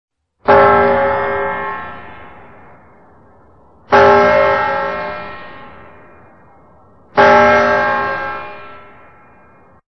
centennial_bell.mp3